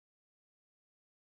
silence.wav